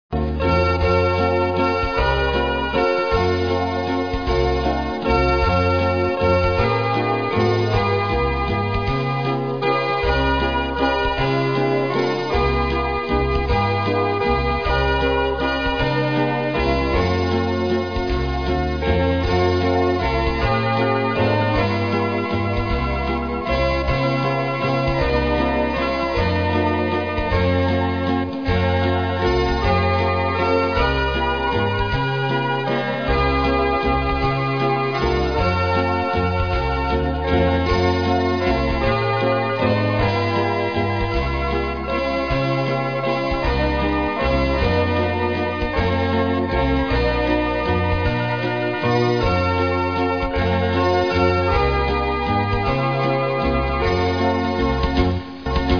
TOURRETTE-LEVENS > AIRS DU FOLKLORE
L'hymne du pays niçois